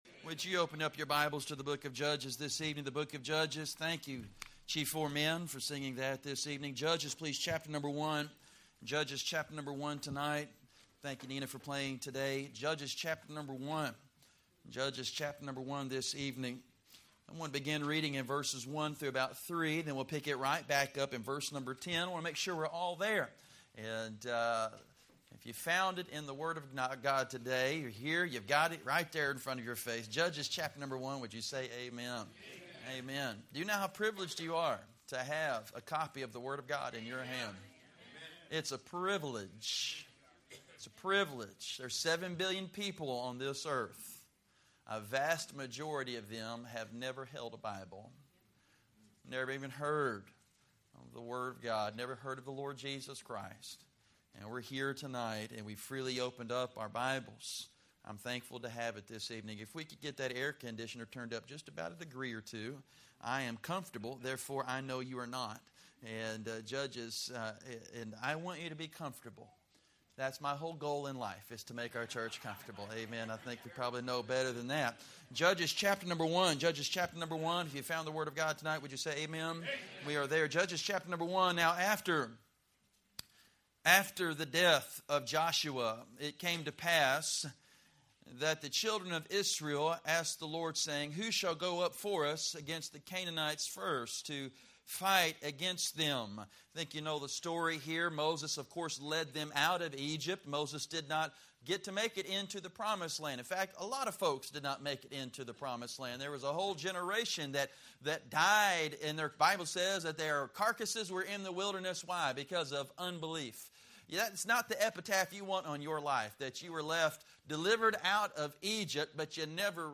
Standalone Sunday Messages at PBC